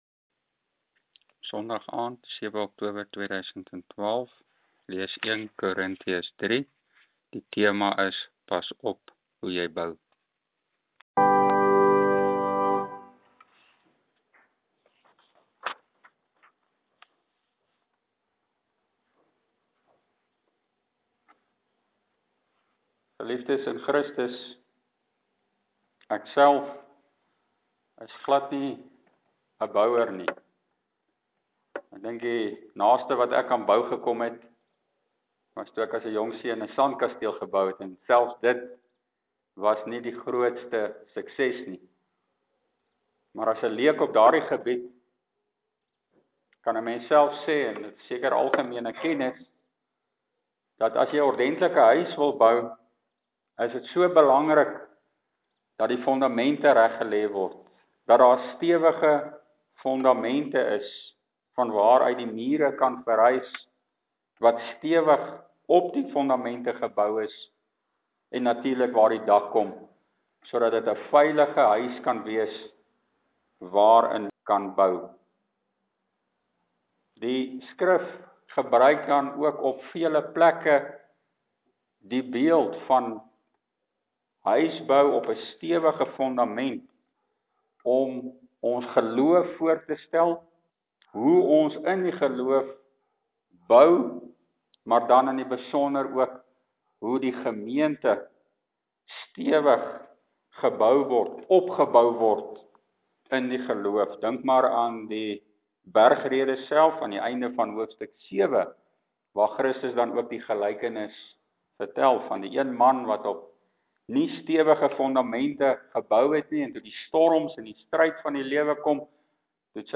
Preke uit 1 Korinthiërs: “maar God het laat groei” (1 Kor.3 en die Omkeerstrategie)